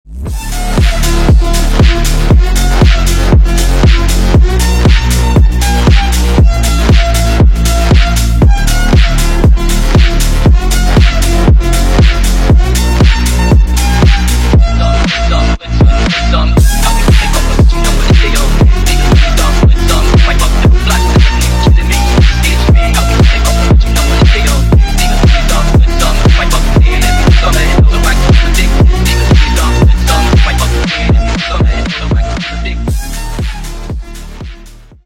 зарубежные клубные